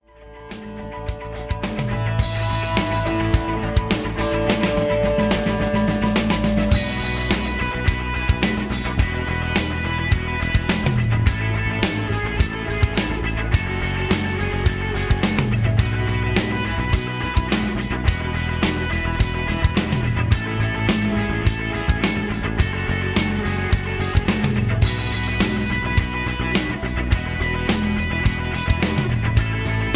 mp3 backing tracks
rock and roll